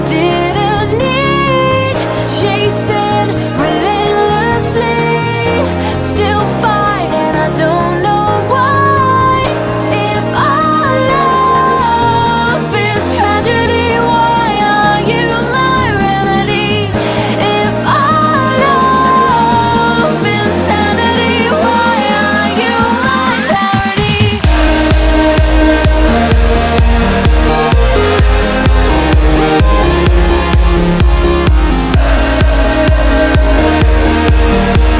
Жанр: Танцевальные / Электроника / Рок / Хаус